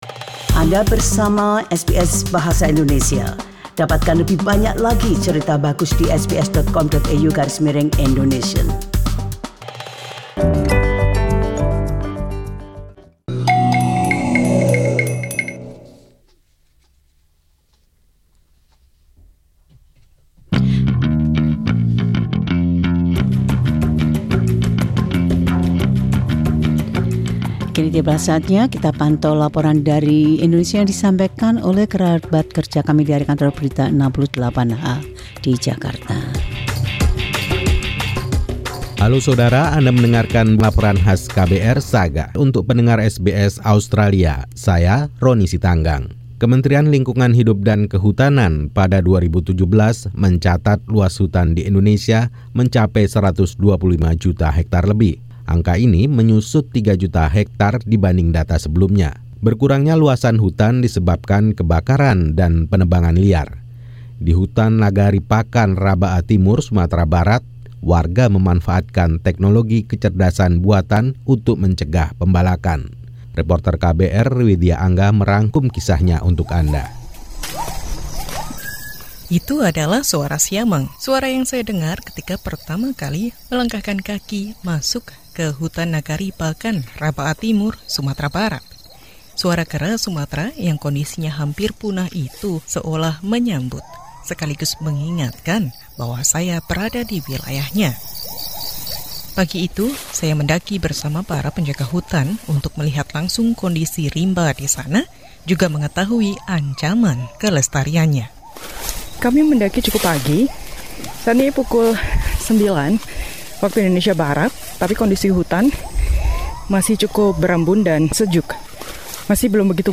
Tim KBR 68H melaporkan penggunaan teknologi kontemporer untukm mencegah pembalakan liar.